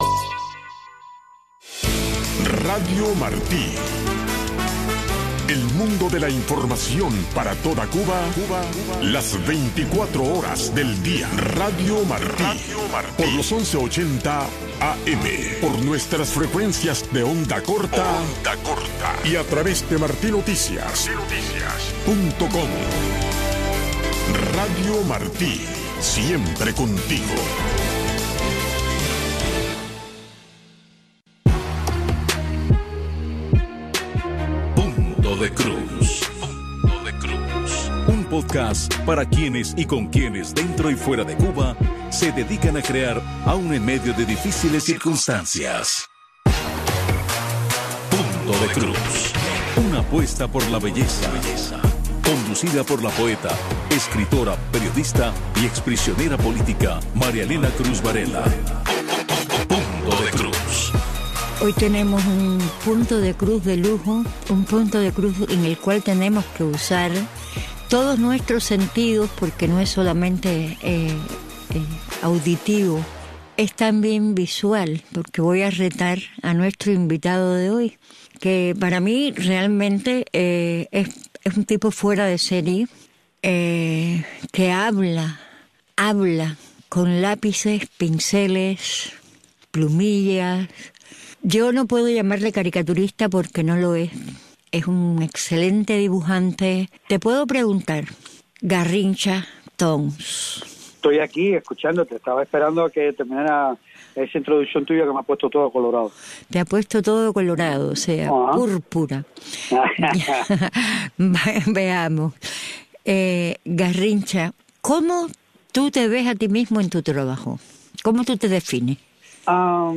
En este espacio informativo de Radio Martí escuche de primera mano los temas que impactan el día a día de los cubanos dentro de la Isla. Voces del pueblo y reportes especiales convergen para ofrecerle una mirada clara, directa y actual sobre la realidad cubana.